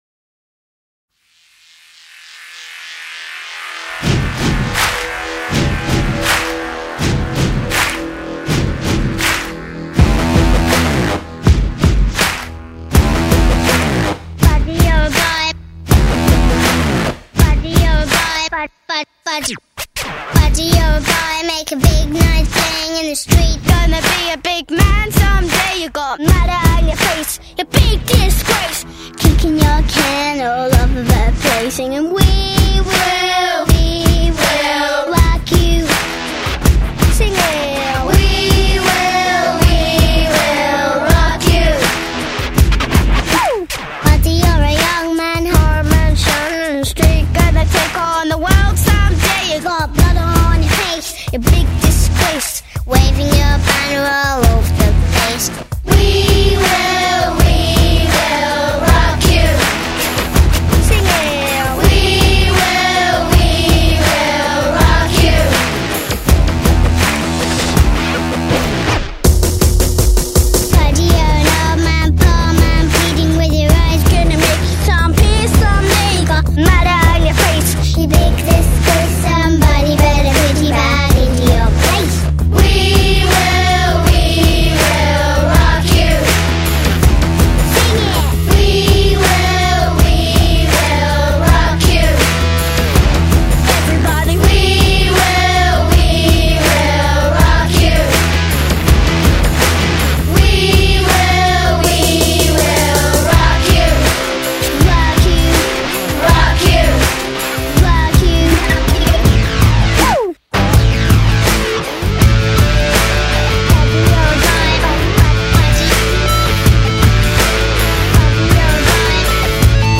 这首雄壮激昂的